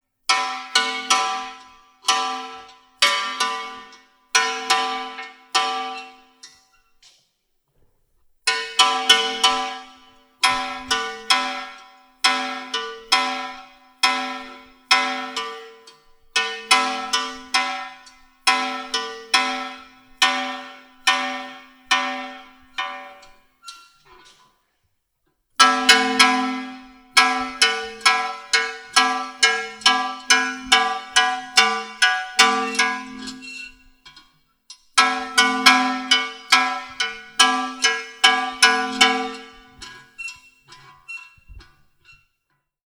01Ramsach_Glocke_v001.wav